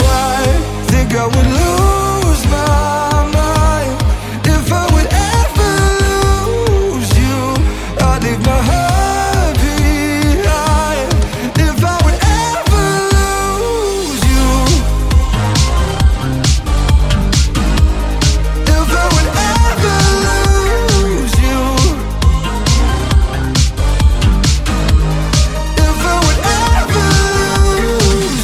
Genere: pop,deep,remix